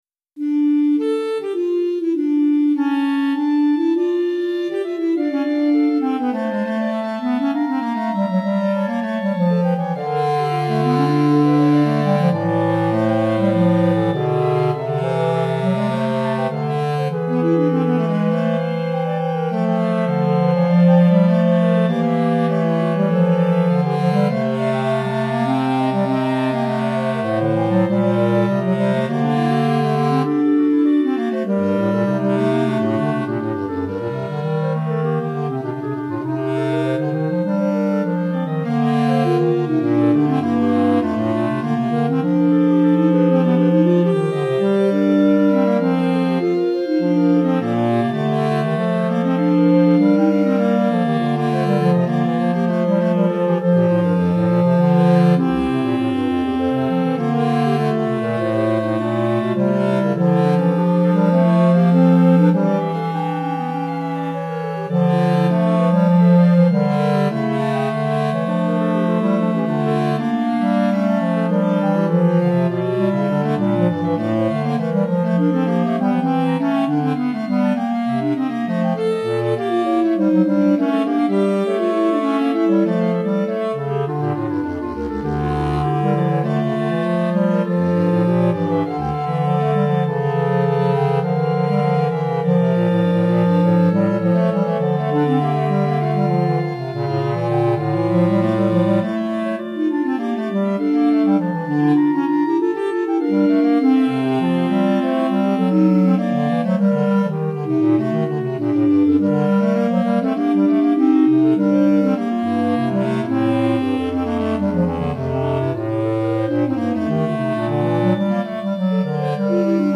4 Clarinettes